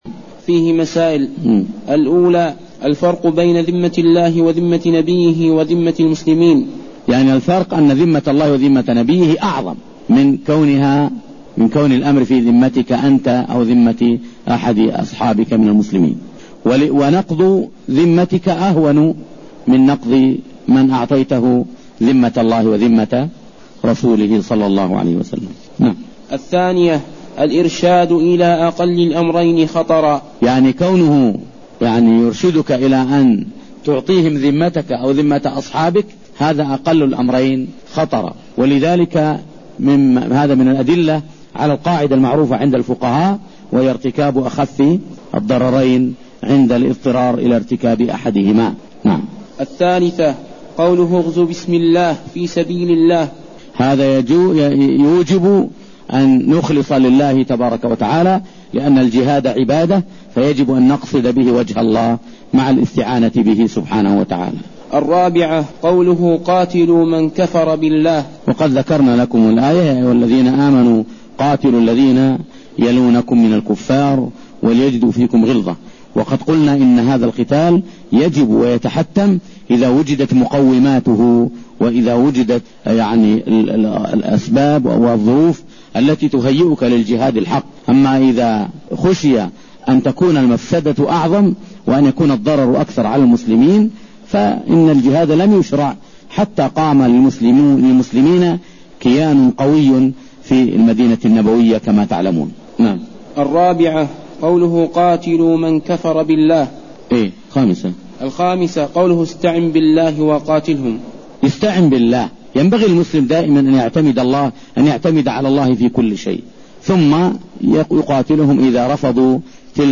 شرح كتاب التوحيد الذي هو حق الله على العبيد الدرس عدد 178